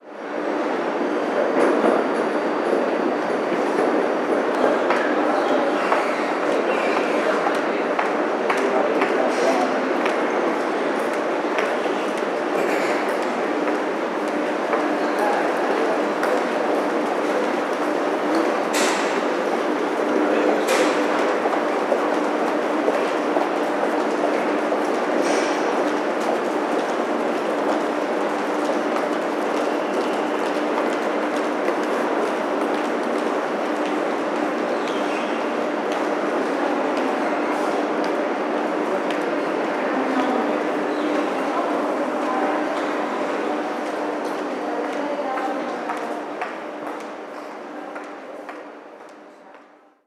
Hall del Metro
subterráneo
Sonidos: Gente
Sonidos: Transportes
Sonidos: Ciudad